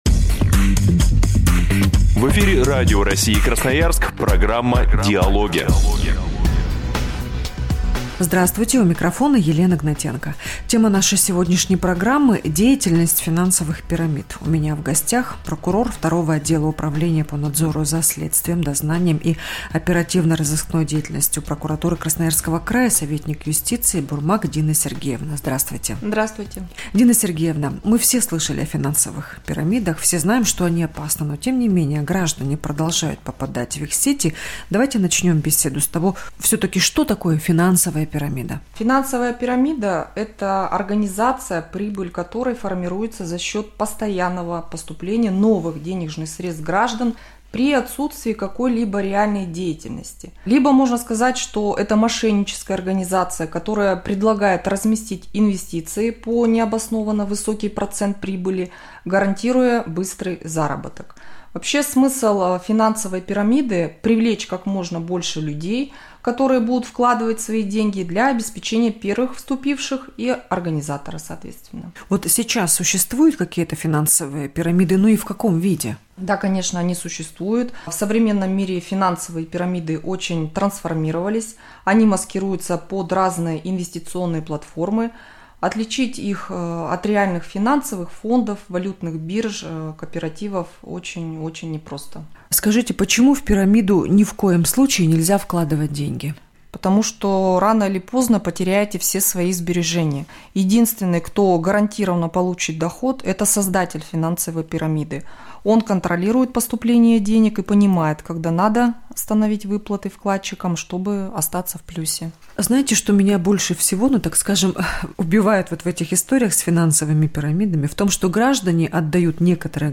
в эфире «Радио России. Красноярск» в рамках рубрики «Дежурный прокурор» программы «Диалоги»